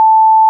• Demo program: makeA440.cpp creates a wavefile (named 'a440.wav') that will sound a 440-HZ tone
plays a pure sinewave tone having a frequency of 440 cycles-per-second
a440.wav